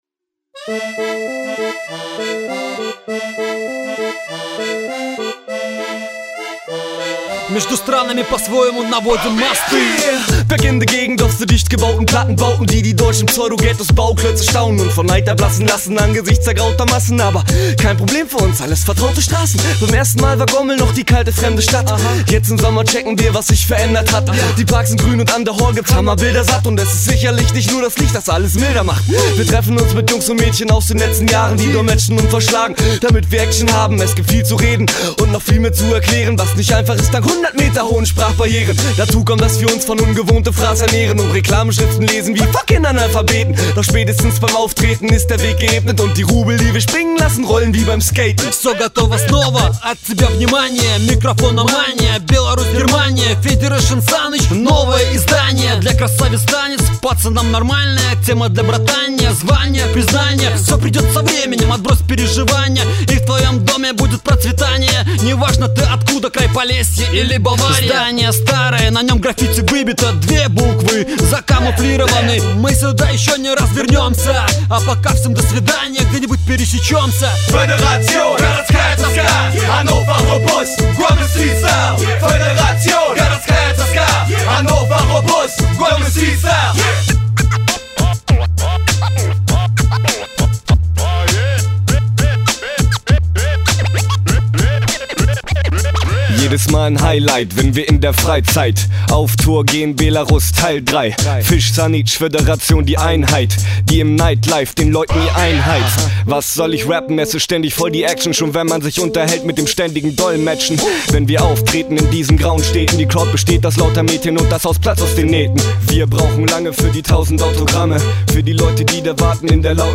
Композиция гомельской хип-хоп гр.